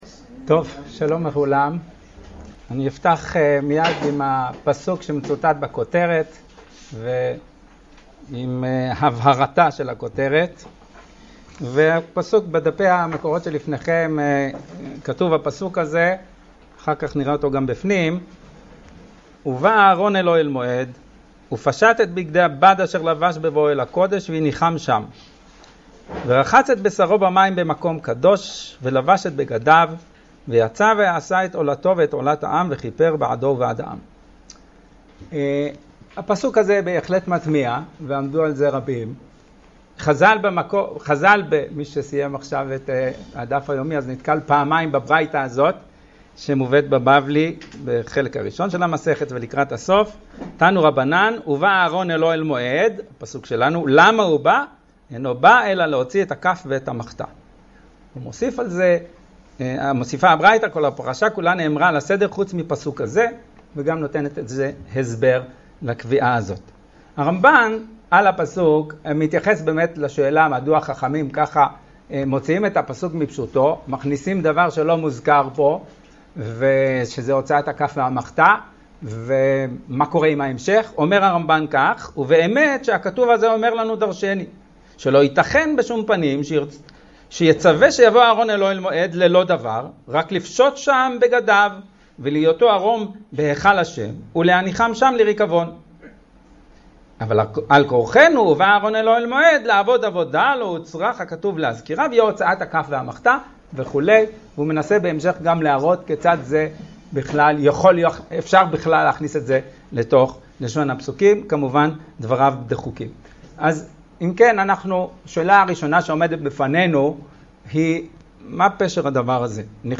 השיעור באדיבות אתר התנ"ך וניתן במסגרת ימי העיון בתנ"ך של המכללה האקדמית הרצוג תשפ"א